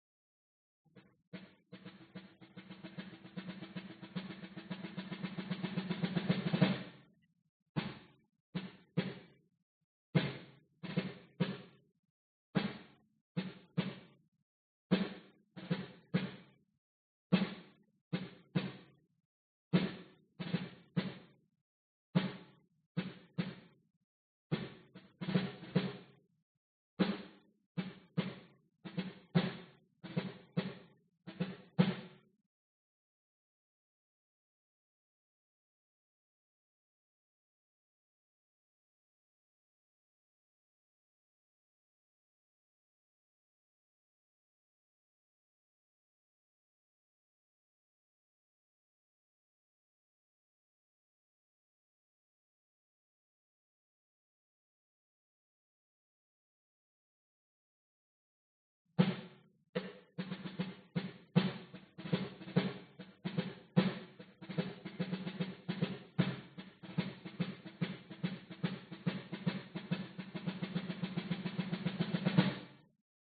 Bit o' Snare
It's a little loose.
标签： snare military war battle